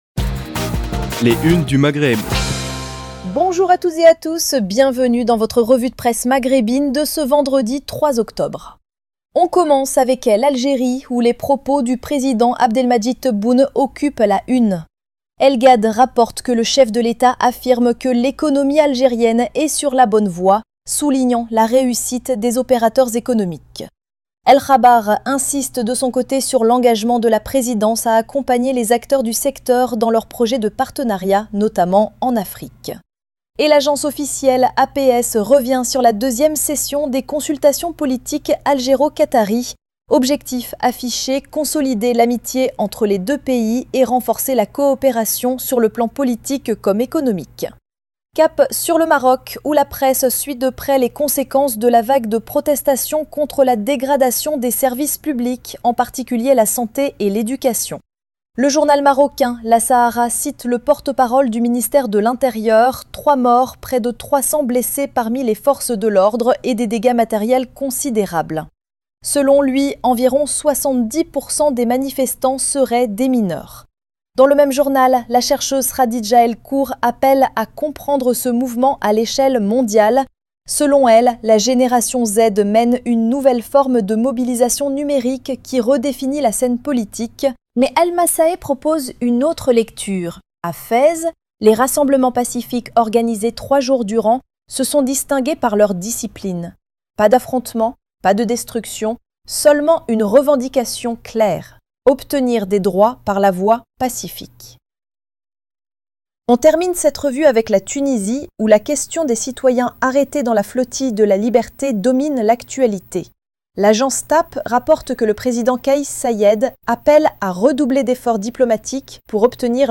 Revue de presse des médias du Maghreb